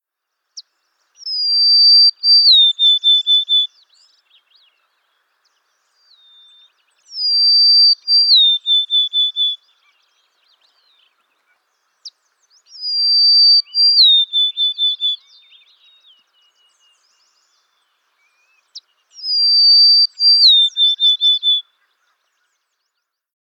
Golden-crowned Sparrow
The Golden-crowned Sparrow’s depressing, tired song earned it the nickname “Weary Willie” back in the early twentieth century. How they sound: These birds have a downward sliding song of melancholy whistles.